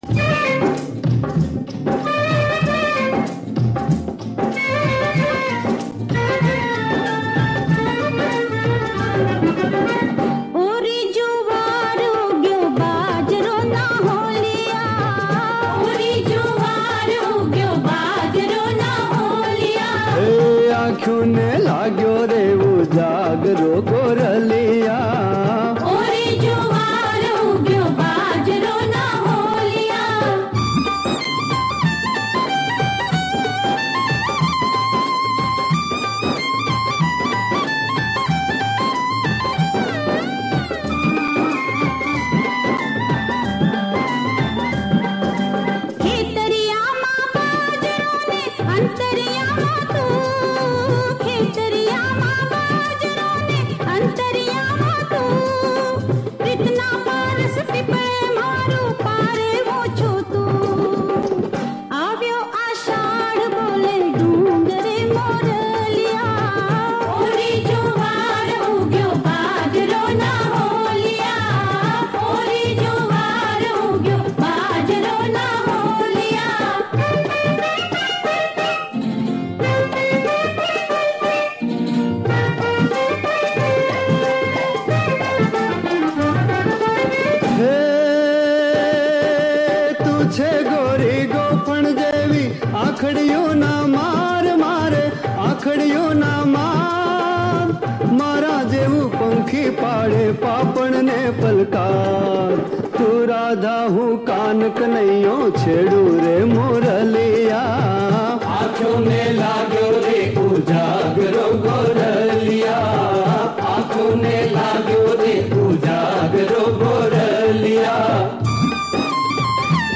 ગીત સંગીત લોક ગીત (Lok-Geet)